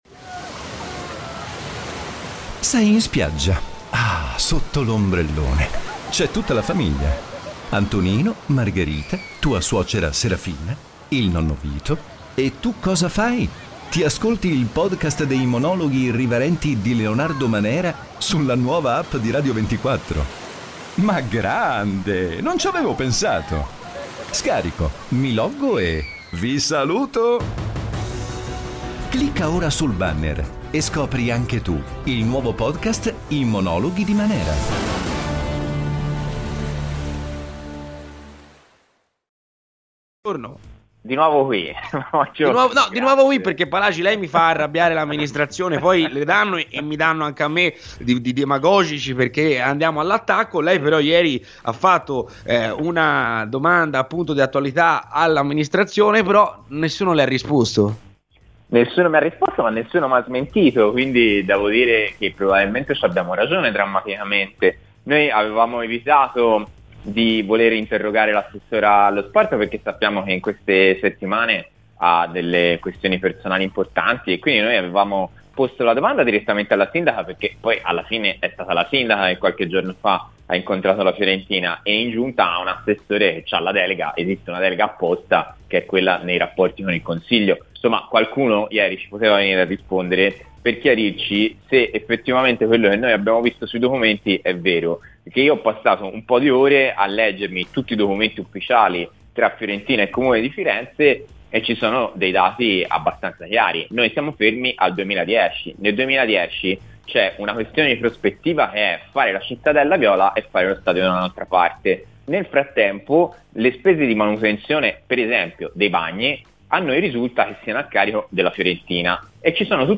Il Consigliere Comunale di Firenze e membro di Sinistra Progetto Comune, Dimitri Palagi è intervenuto ai microfoni di Radio FirenzeViola durante la trasmissione "C'è polemica".
Dimitri Palagi - Cons. Comunale Sinistra Progetto Comune a RadioFirenzeViola